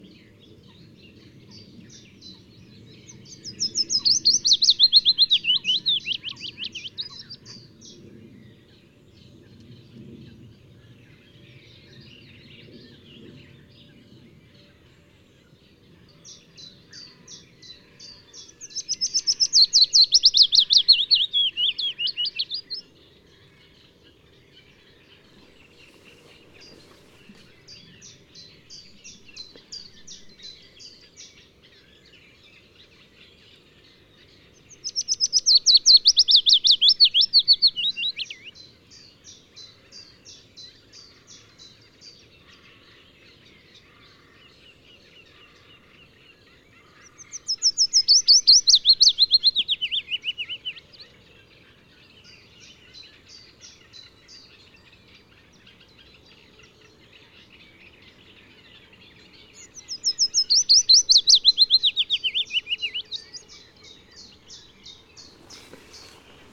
Fitis
Der Fitis gehört zur Gattung der Laubsänger. Von Laien kann er nur anhand des Gesangs vom Zilpzalp unterschieden werden.
Hören Sie hier die Stimme des Fitis.
Fitis.mp3